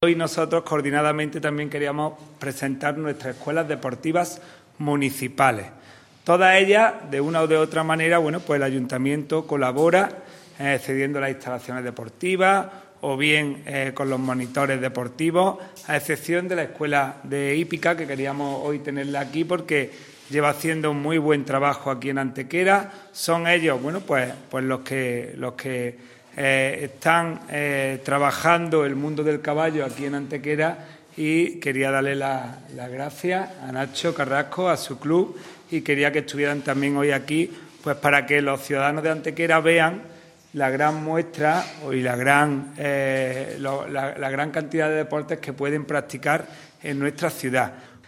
El teniente de alcalde delegado de Deportes, Juan Rosas, ha presentado hoy lunes en rueda de prensa el grueso de las escuelas deportivas municipales que compondrán la oferta al respecto del Área de Deportes del Ayuntamiento de Antequera.
Cortes de voz